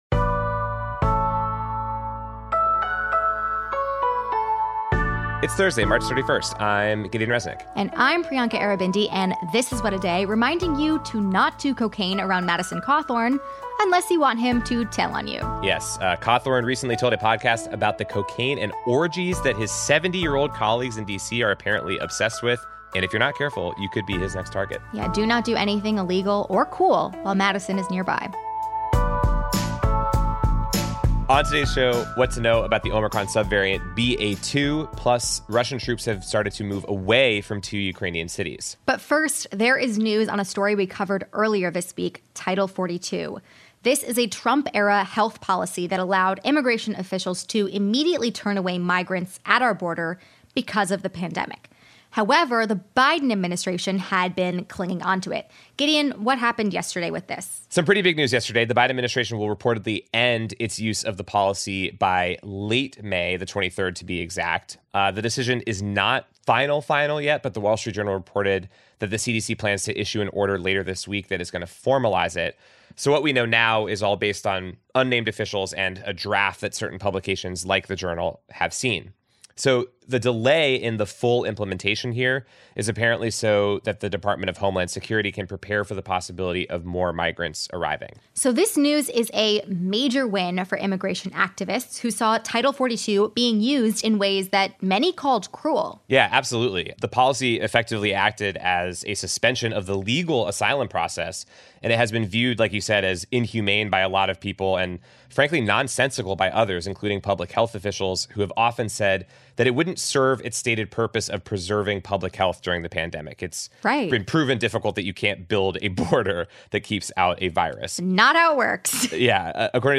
The BA.2 Omicron subvariant is now the dominant COVID strain in the U.S. This comes as many states begin to close mass vaccination and testing sites that were vital throughout the pandemic. Dr. Céline Gounder, an infectious disease specialist & epidemiologist, joins us to give us her perspective on the matter.